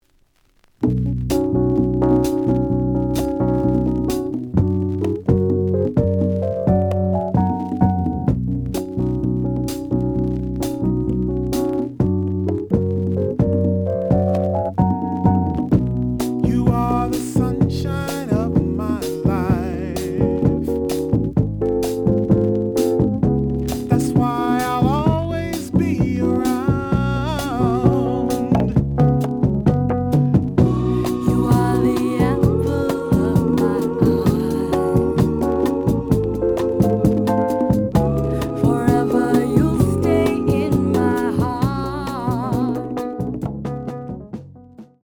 The audio sample is recorded from the actual item.
●Genre: Soul, 70's Soul
Slight noise on beginning of A side, but almost good.